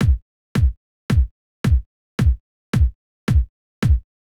03 Kick.wav